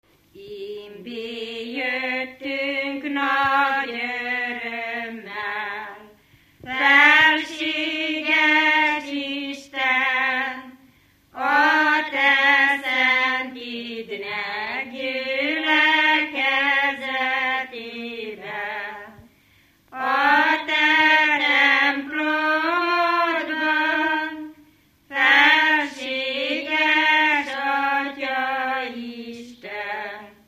Dunántúl - Baranya vm. - Kopács
ének
Stílus: 7. Régies kisambitusú dallamok
Kadencia: 1 3 (1) 5 1